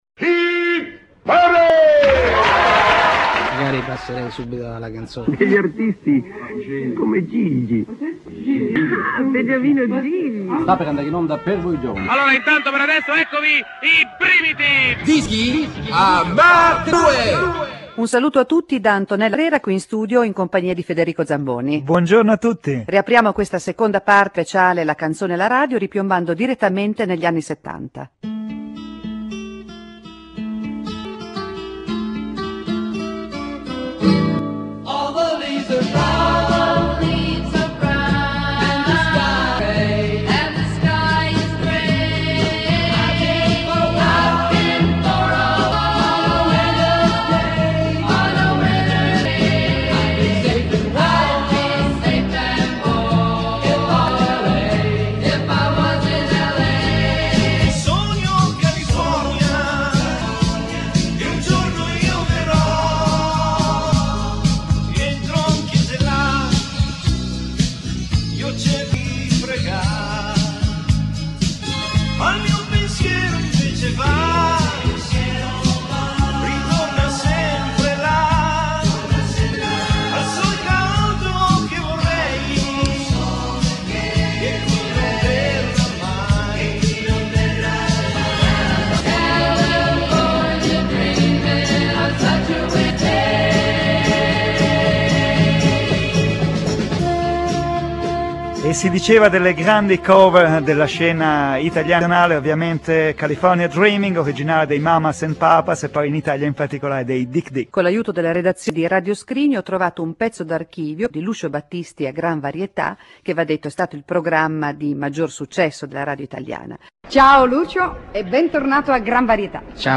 Attraverso i brani dei piu' grandi artisti italiani e internazionali, con pezzi di repertorio delle trasmissioni radiofoniche